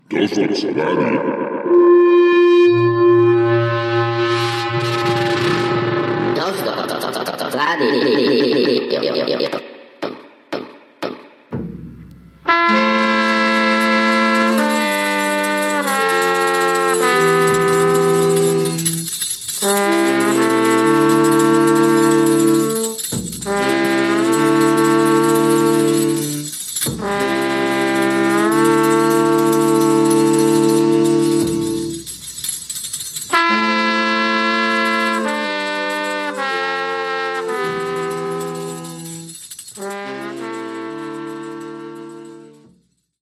Indicatiu de l'emissora i tema musical.